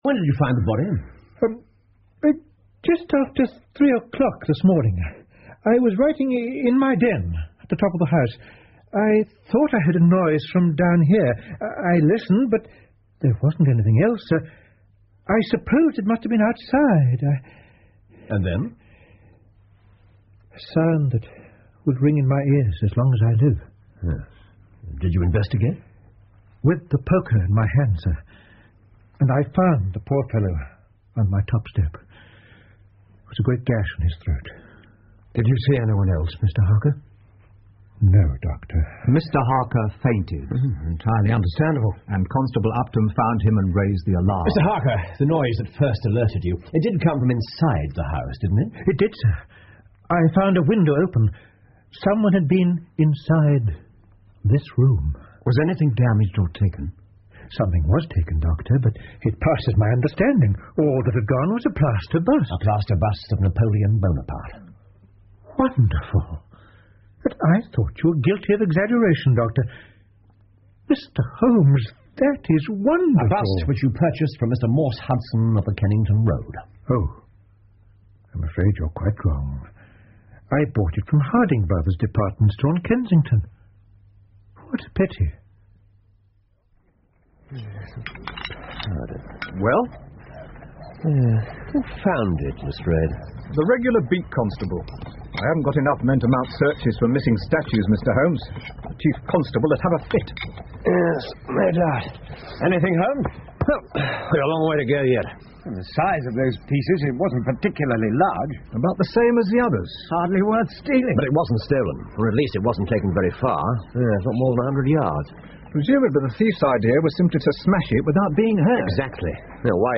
福尔摩斯广播剧 The Six Napoleons 4 听力文件下载—在线英语听力室